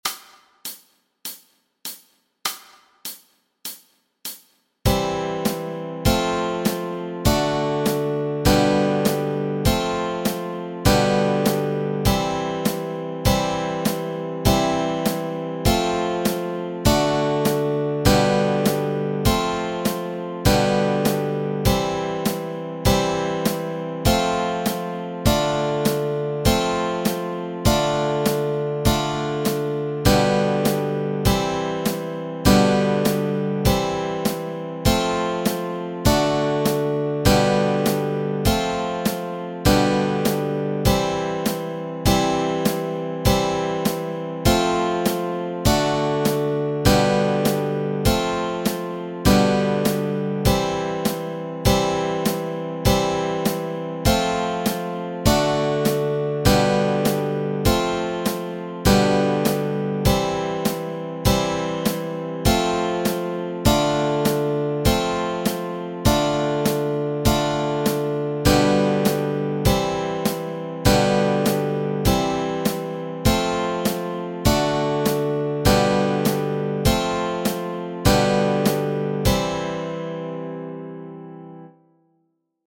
Chords Track
All-Through-the-Night-chords.mp3